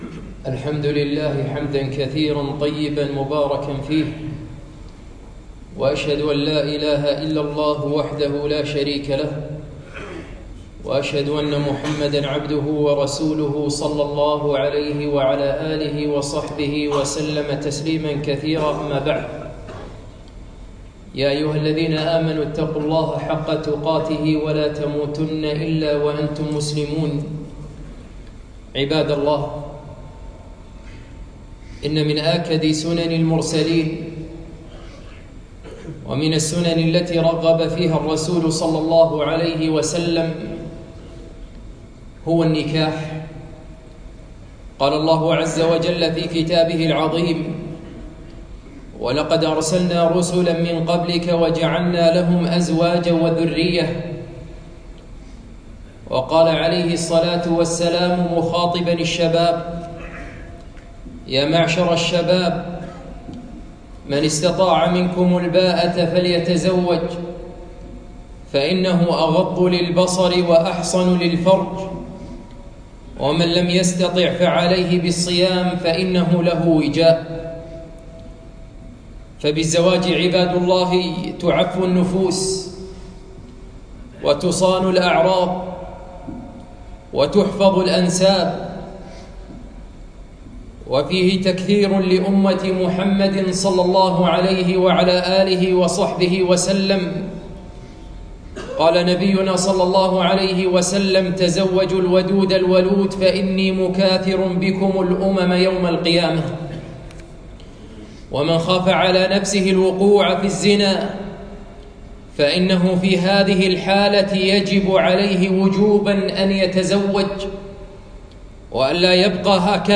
خطبة - من سنن النكاح